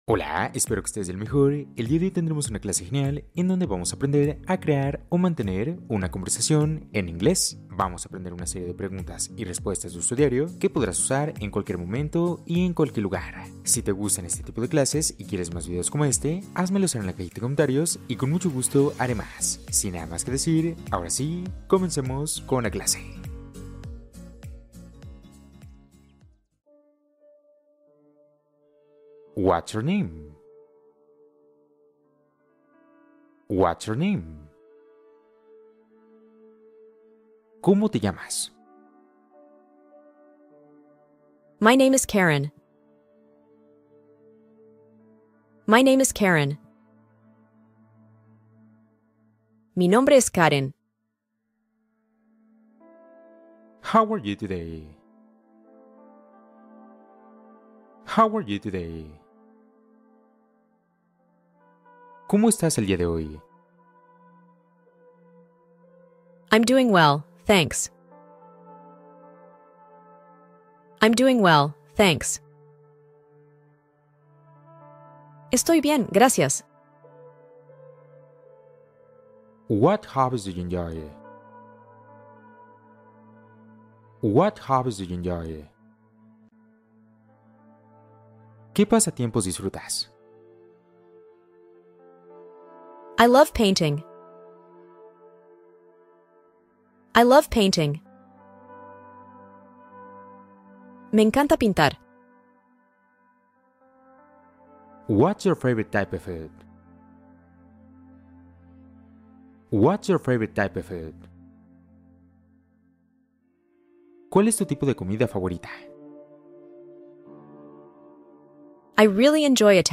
Escucha esta conversación en inglés y aprende rápido | Método fácil